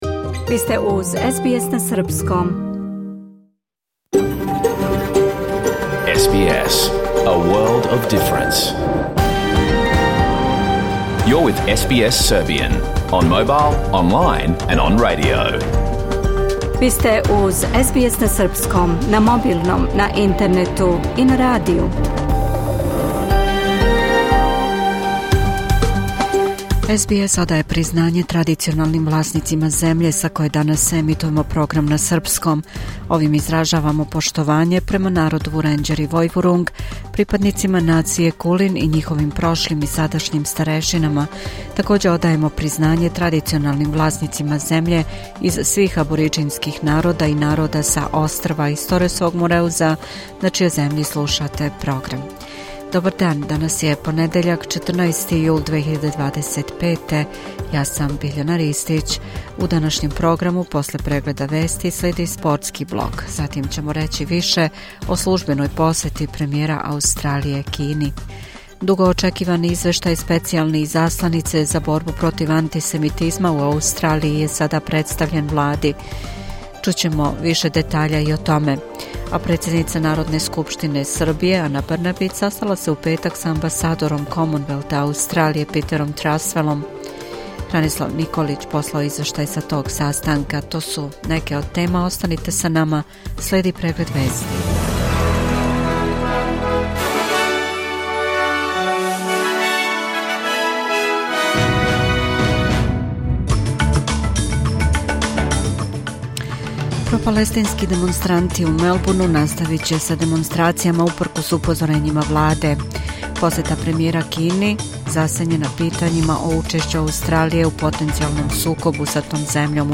Serbian News Bulletin Source: SBS / SBS Serbian